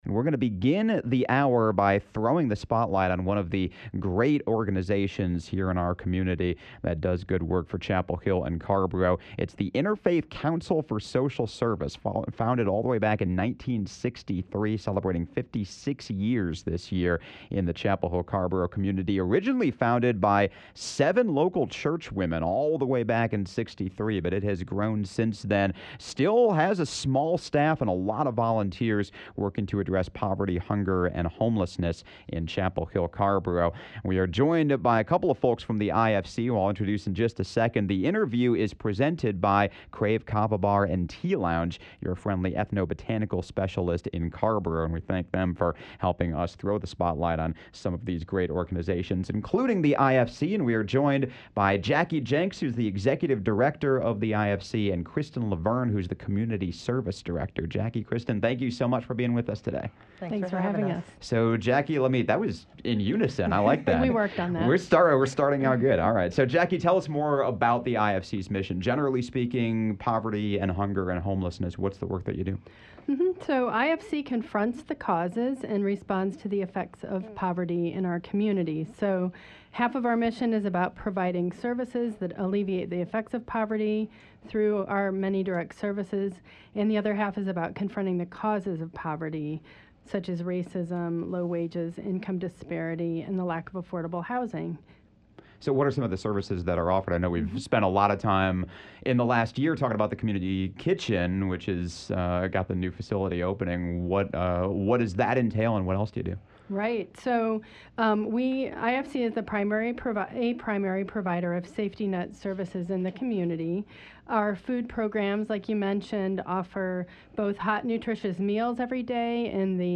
Conversation sponsored by Krave Kava Bar and Tea Lounge.